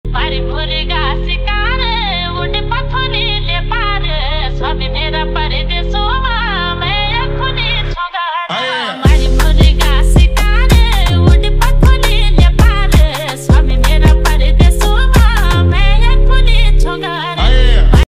Authentic, vibrant Garhwali music to personalize your phone.
pahadi song